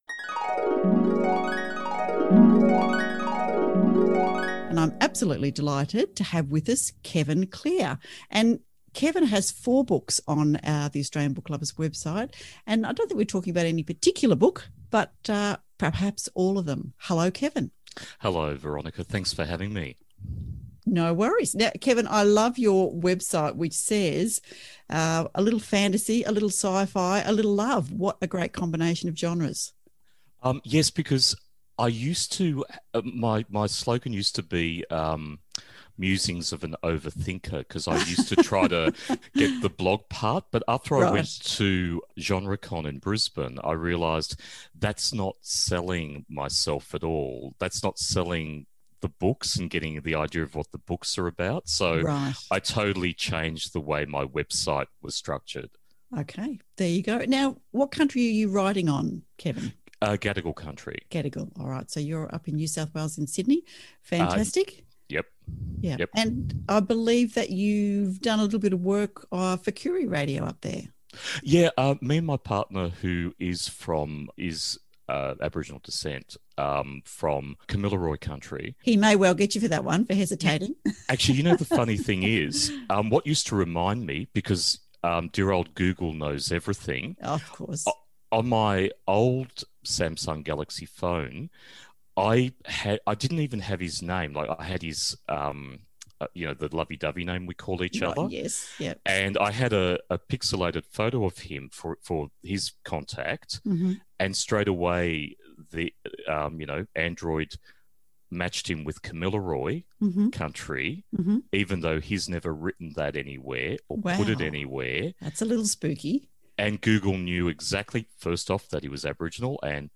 News, Interviews and Guest Blogs